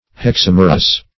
Hexamerous \Hex*am"er*ous\, a.